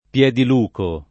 [ p LH dil 2 ko ]